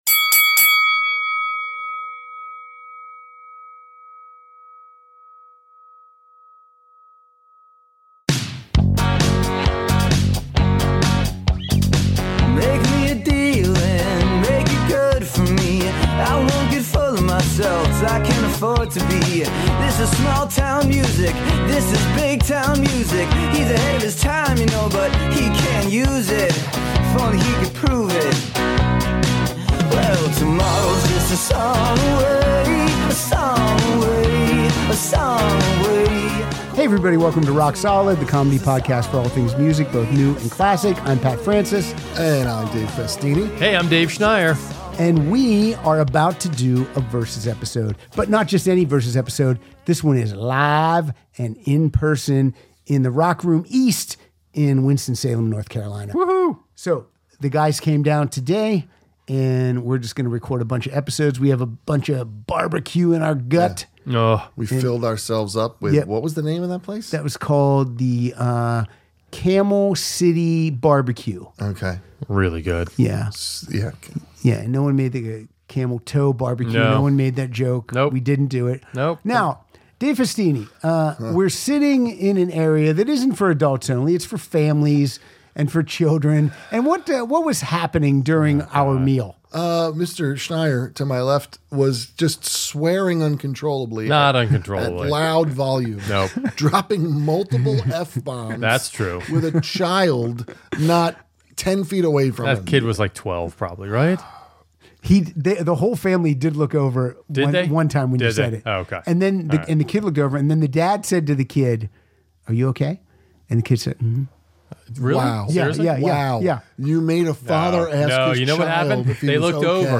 This one was recorded LIVE and IN PERSON at the "Rock Room East" in NC.